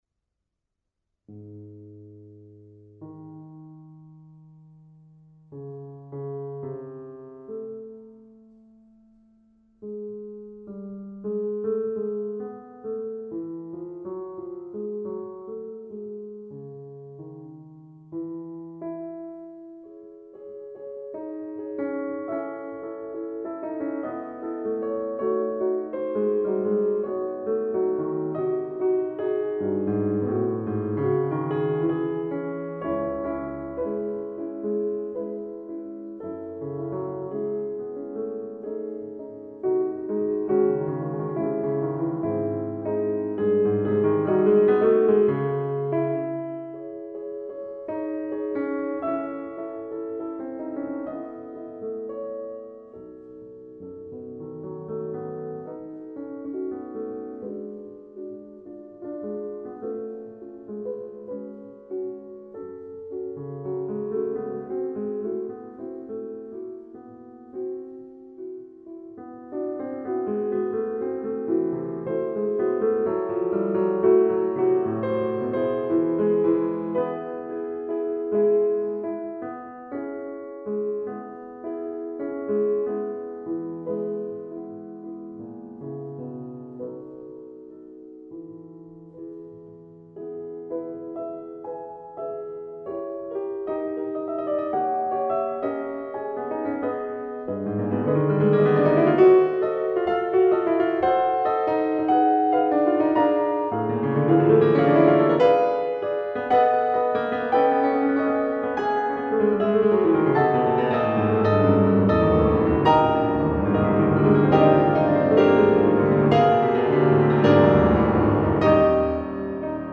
piano -